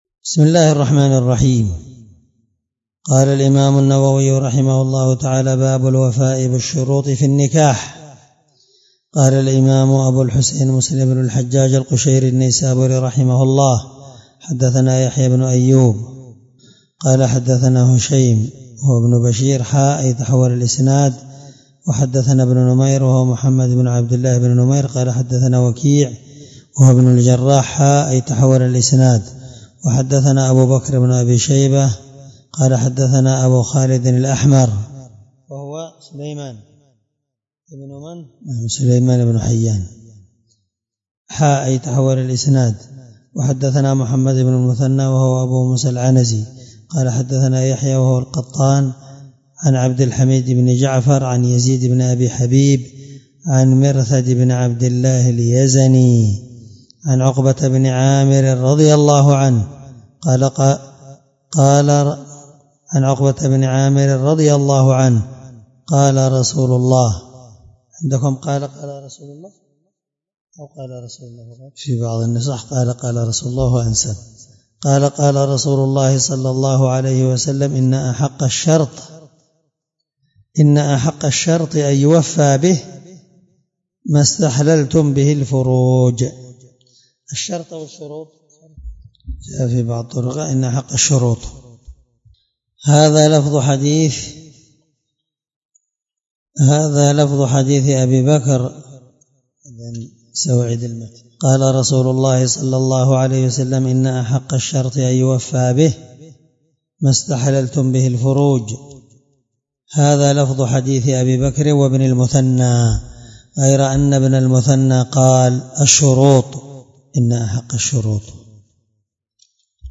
الدرس15من شرح كتاب النكاح حديث رقم(1418) من صحيح مسلم